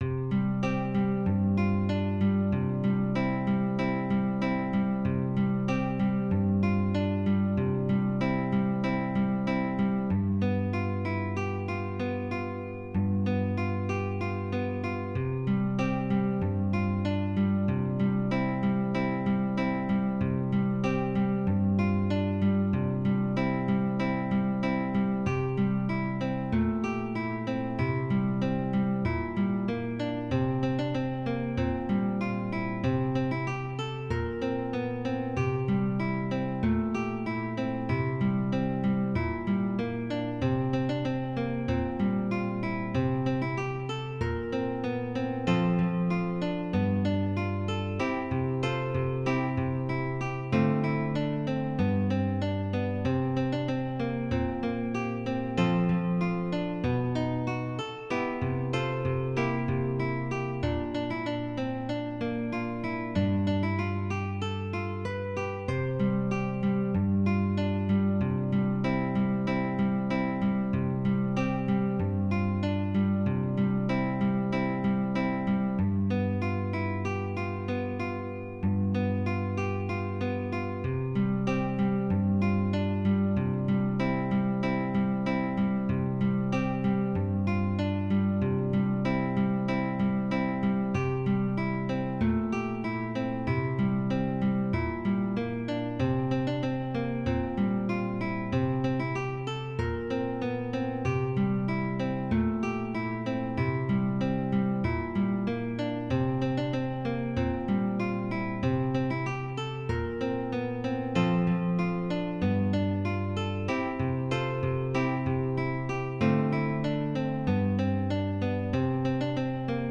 This is a PSF-to-MP3 conversion.